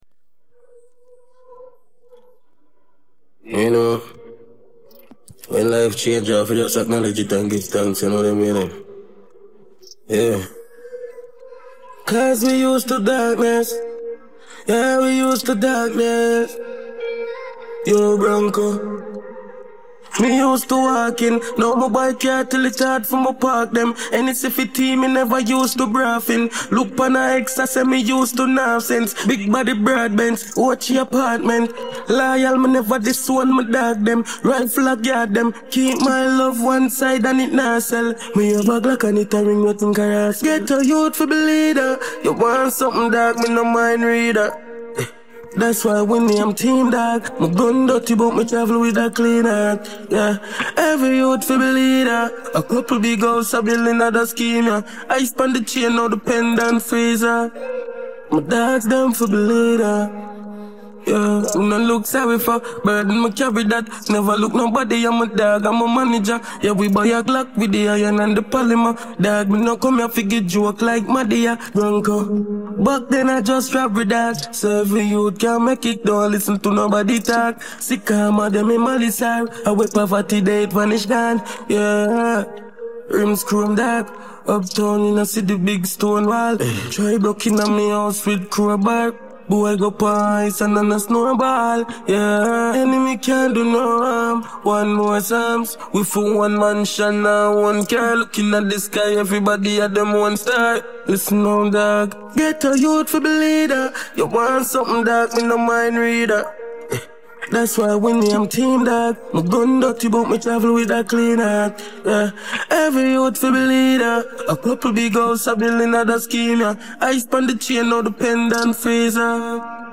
Vokaldel